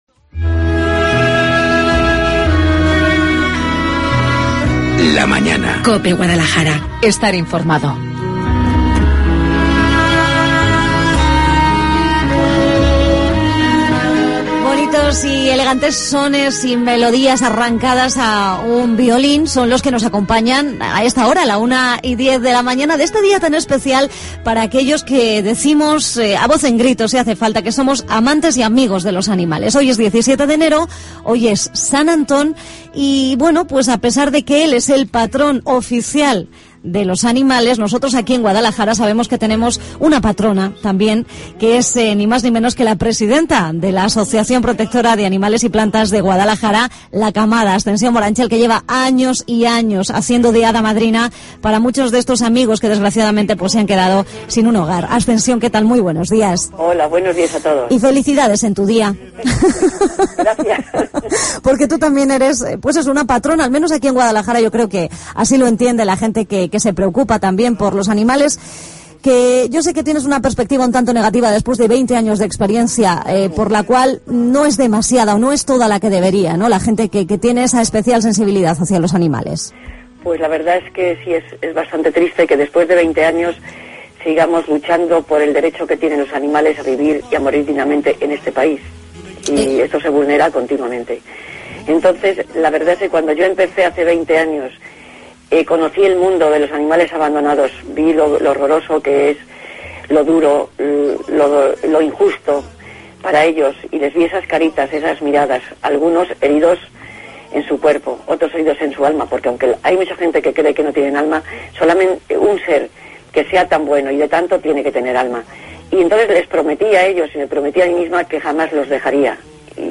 Seguimos hablando de San Antón, de la vida del santo y de la bendición de animales en la Parroquia de Santiago de la capital en conexión telefónica